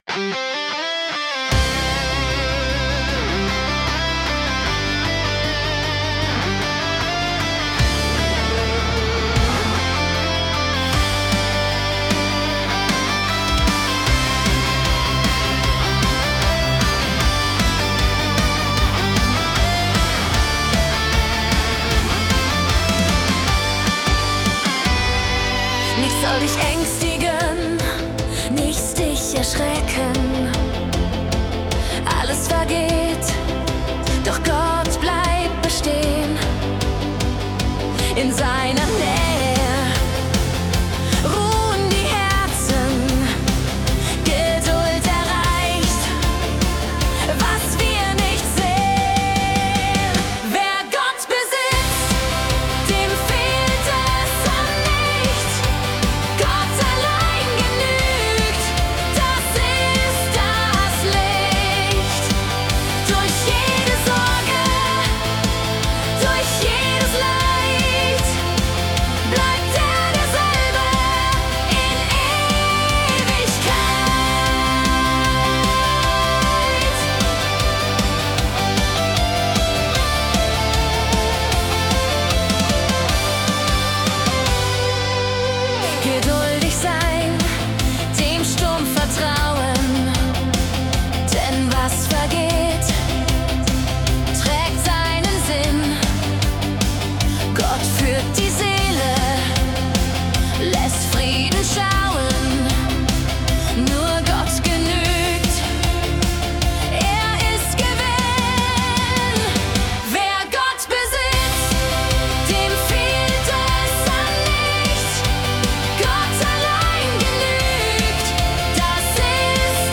Traditional Heavy MetalBPM ~164